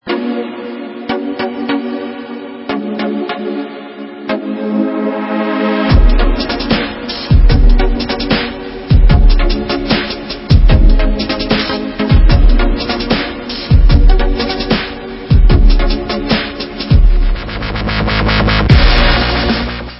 POST-HARDCORE WITH ELECTRONIC APPROACH